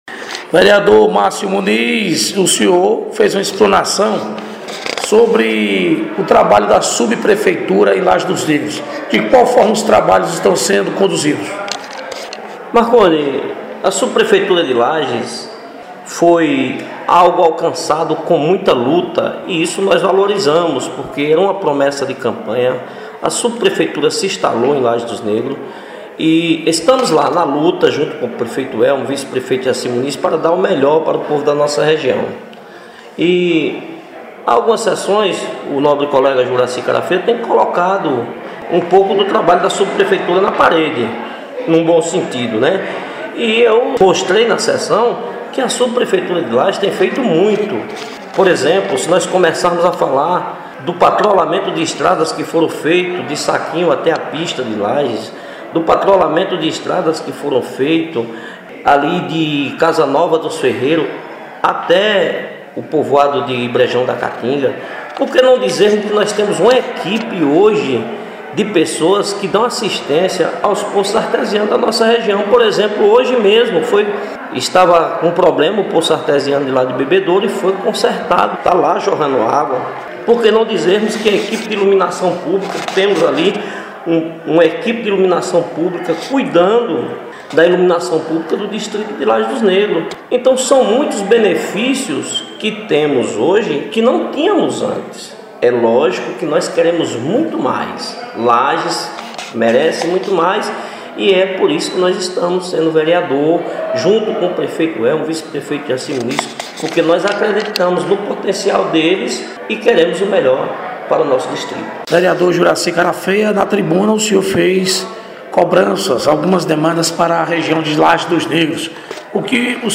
Reportagem: Vereadores de CFormoso